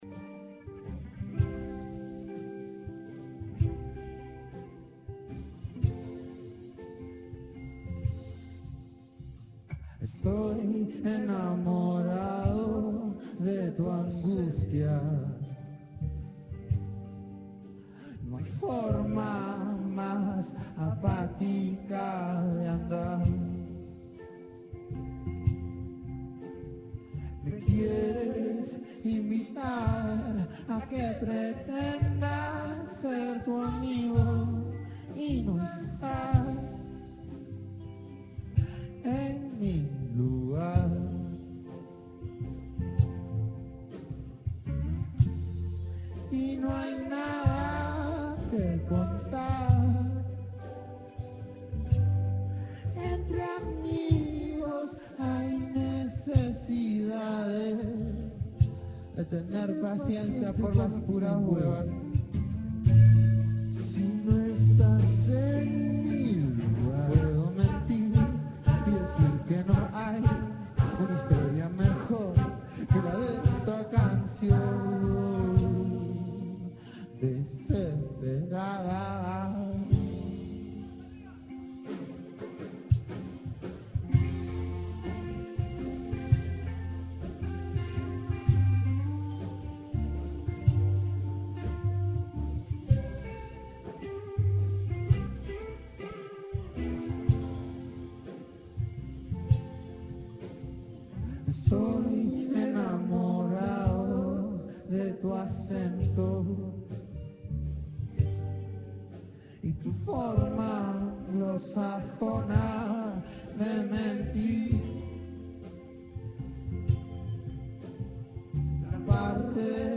nterview conducted during Rock in the Park Festival 2017 with members of the Mexican band La Santa Cecilia. The musicians express their excitement about returning to Bogotá and participating once again in the festival, highlighting the importance of connecting with the audience and sharing their music as a cultural gift. They discuss their album “Amar y Vivir,” recorded live in Mexico with classic boleros and rancheras, and reflect on their role as representatives of the Latin community in the United States amid an adverse political context.